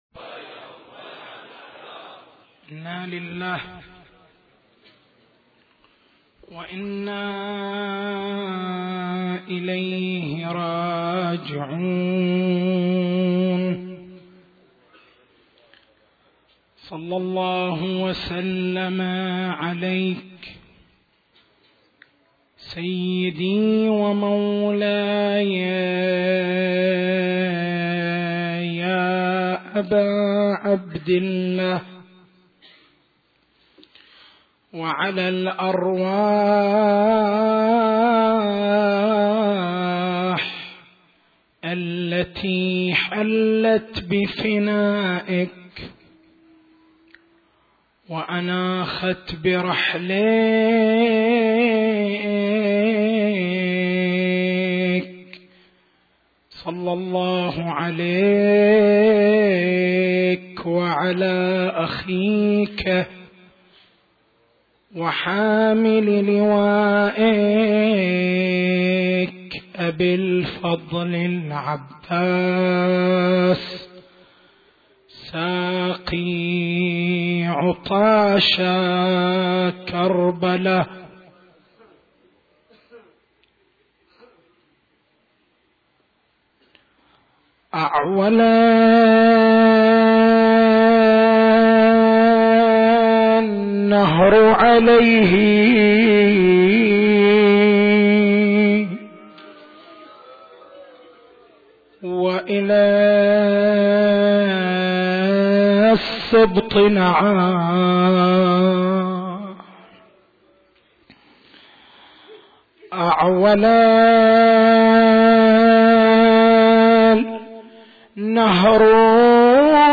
تاريخ المحاضرة: 07/01/1432 نقاط البحث: هل الإمامة من أصول الدين، أم من أصول المذهب؟